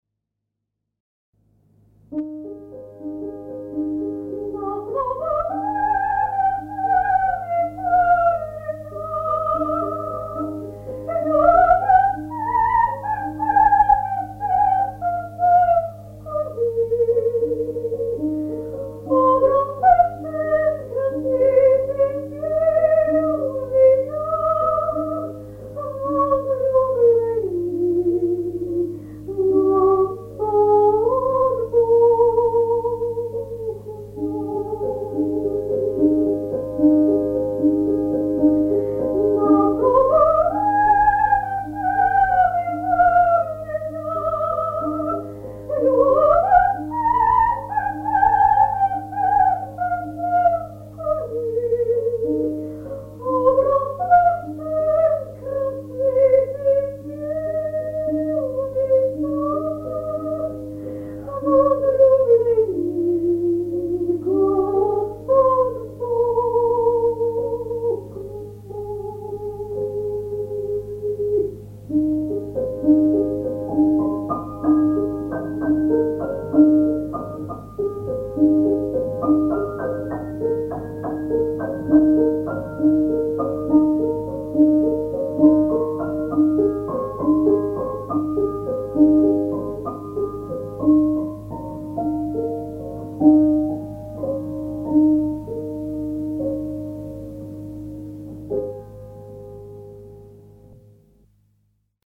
Dieci temi – Questo è il quarto dei dieci temi strumentali inseriti nella raccolta dei canti, non abbinato ad alcun testo.
Deset temi – Dieci temi – 4 Spartito non ancora disponibile Versione strumentale Deset temi - 04 Deset temi - 04 (versione vocale non disponibile) Testo traslitterato Tema n° 4 Si tratta di una melodia strumentale, senza testo.